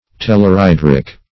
Meaning of tellurhydric. tellurhydric synonyms, pronunciation, spelling and more from Free Dictionary.
Search Result for " tellurhydric" : The Collaborative International Dictionary of English v.0.48: Tellurhydric \Tel`lur*hy"dric\, a. (Chem.)